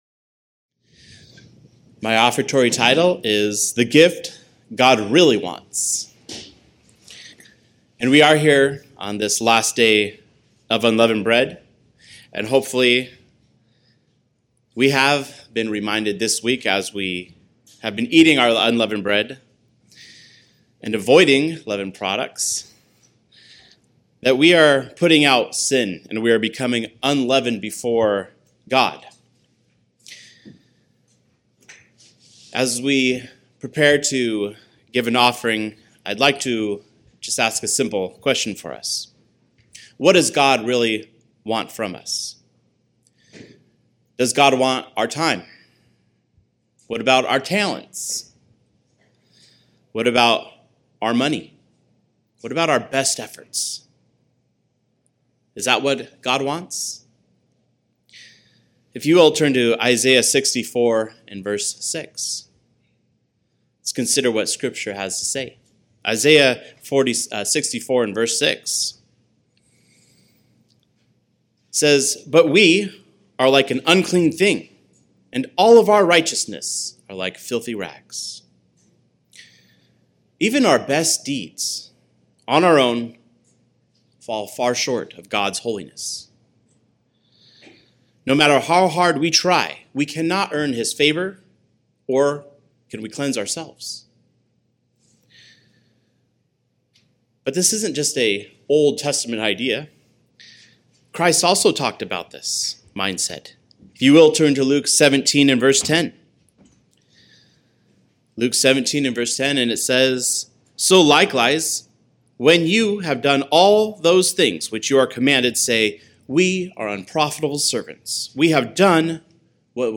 Sermons – Page 8 – Church of the Eternal God